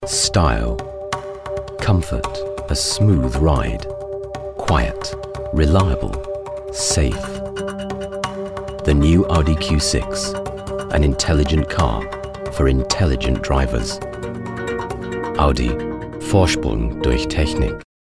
Male
Foreign Language
Car Ad With German Tag Line
Words that describe my voice are Conversational, Natural, Versatile.